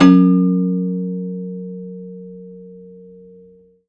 53p-pno04-F0.wav